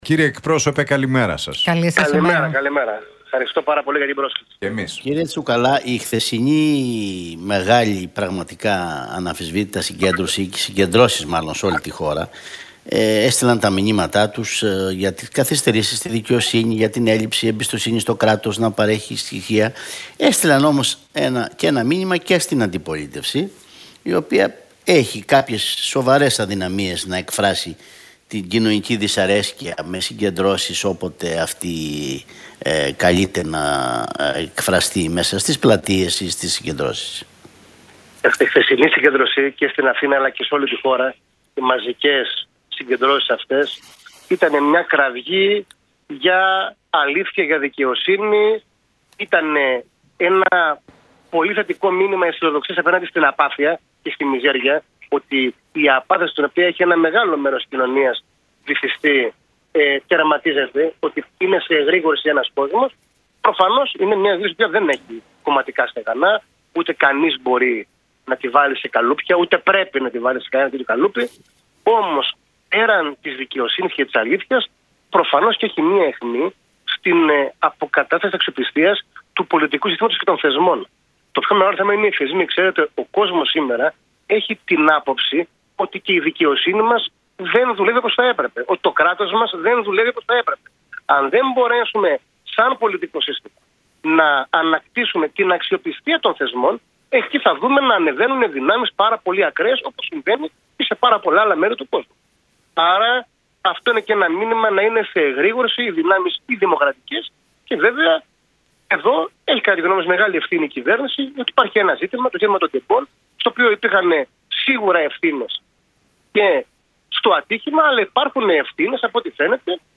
από την συχνότητα του Realfm 97,8.